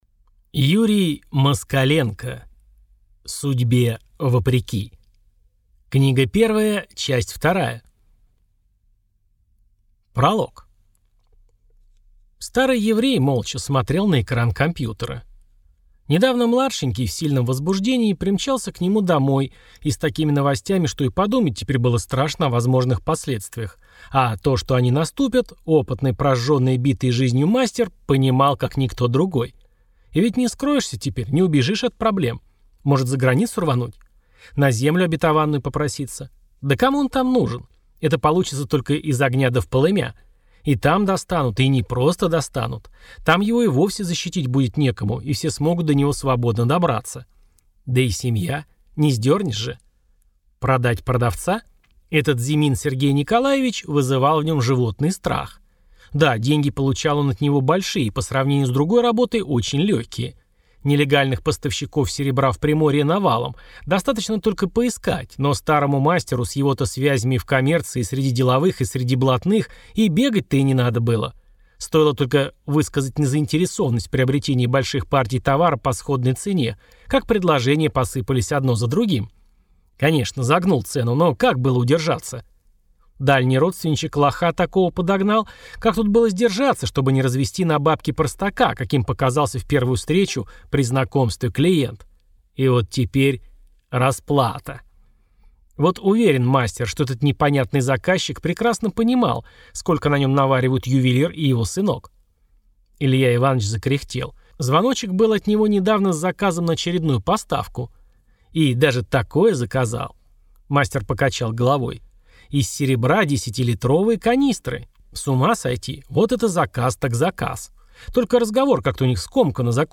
Аудиокнига Судьбе вопреки. Часть вторая. «…главное выжить…»